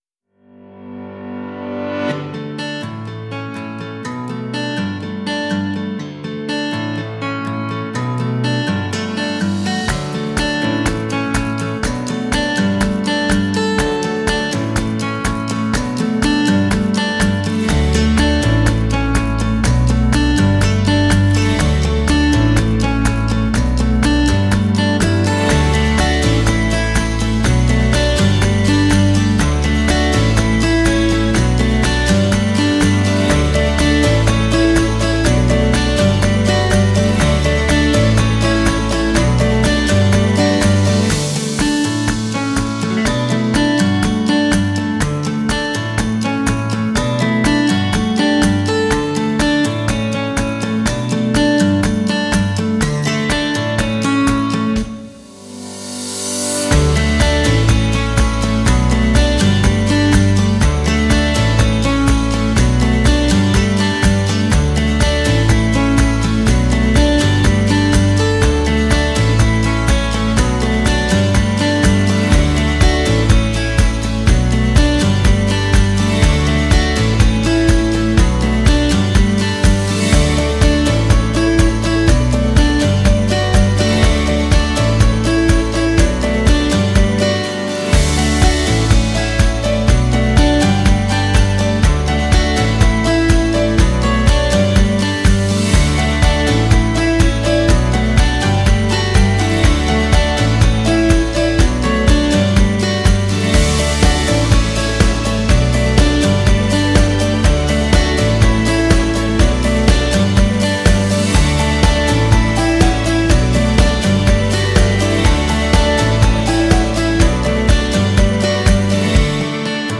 Genre: corporate, folk.